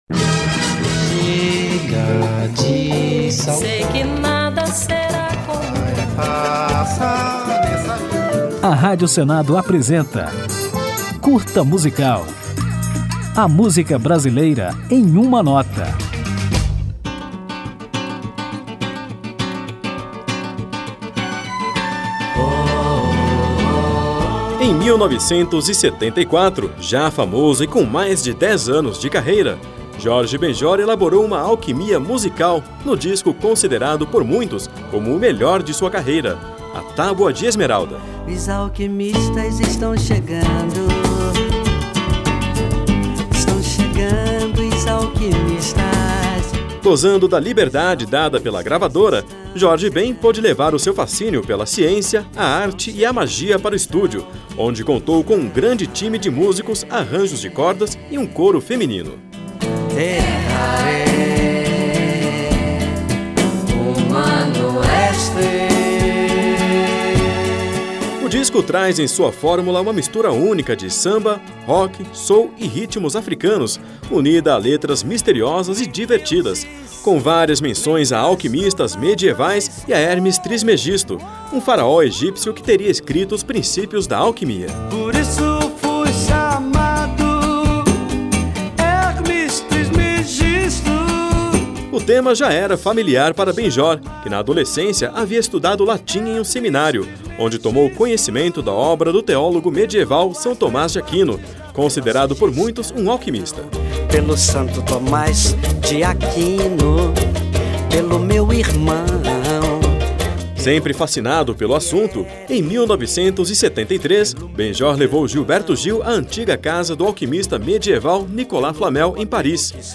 Neste episódio, o Curta Musical destrincha o álbum A Tábua de Esmeralda, de 1974, um dos mais cultuados de Jorge Ben Jor. Aperte o play e confira a história, as curiosidades e os detalhes deste clássico da MPB e ainda ouça Jorge Ben Jor com Os Alquimistas Estão Chegando Os Alquimistas, faixa de abertura do disco.
Samba